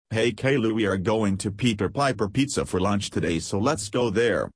Sound Buttons: Sound Buttons View : Cartoon Classic